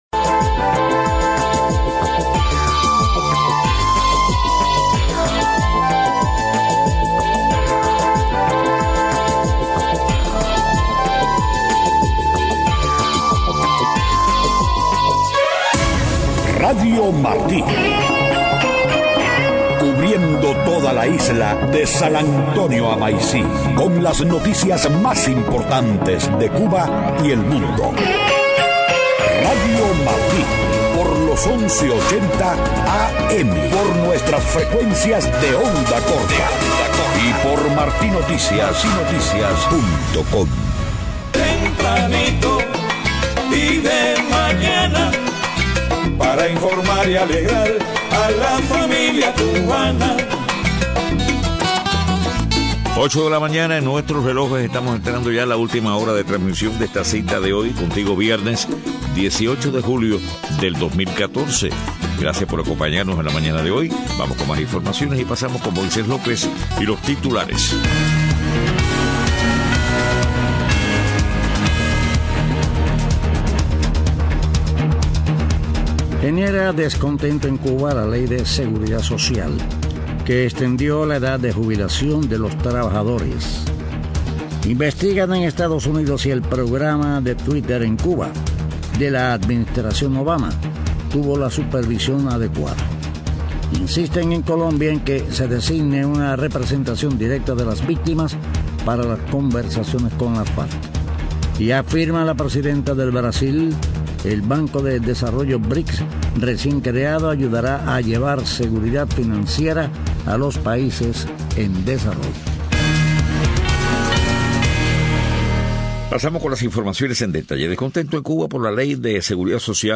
Estado del tiempo.